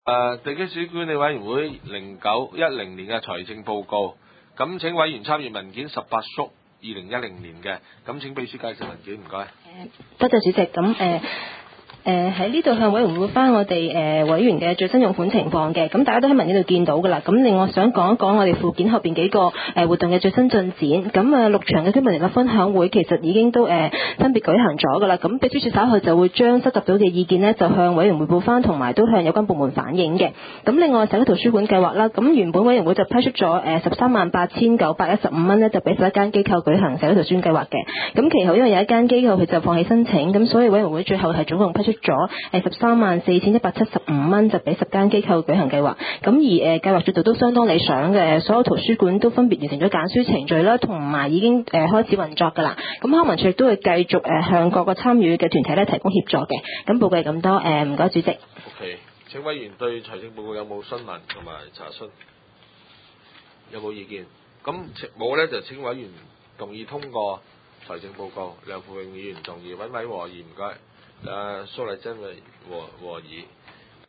地區設施管理委員會地區工程專責小組特別聯合會議記錄
九龍觀塘同仁街6號觀塘政府合署3樓觀塘民政事務處會議室